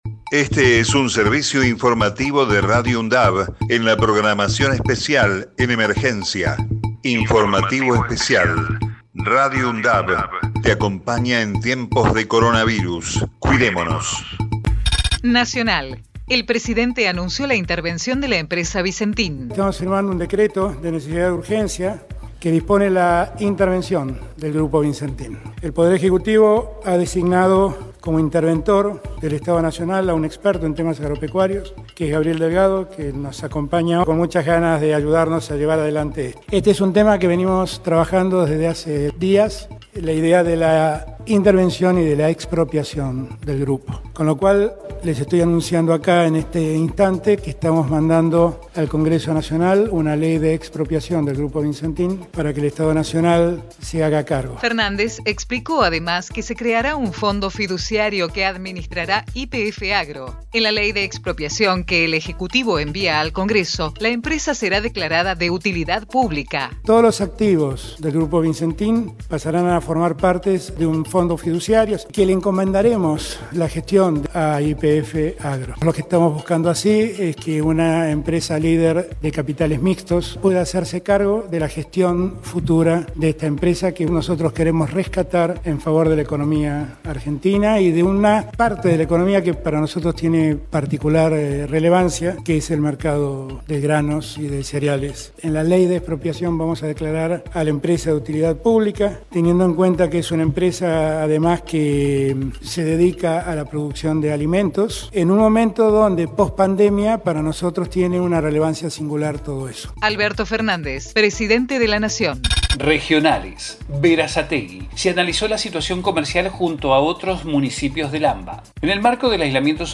COVID-19 Informativo en emergencia 09 de junio 2020 Texto de la nota: Este es un servicio informativo de Radio UNDAV en la programación especial en emergencia.